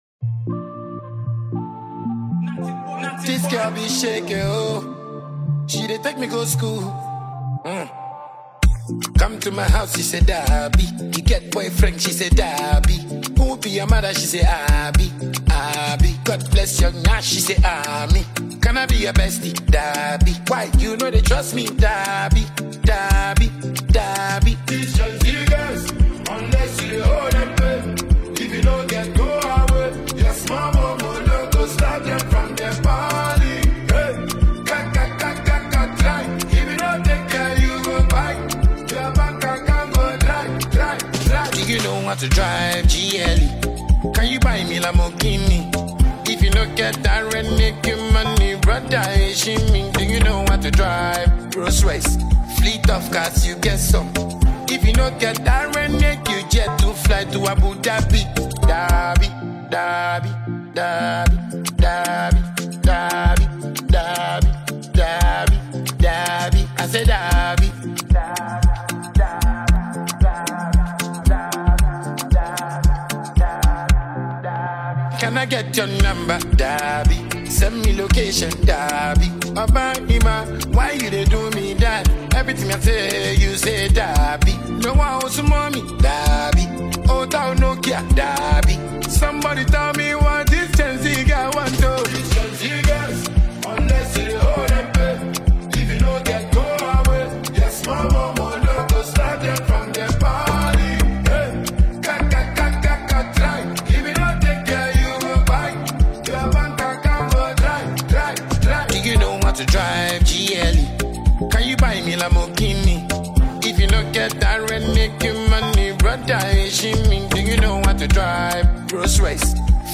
a Ghanaian afrobeats and dancehall songwriter and musician.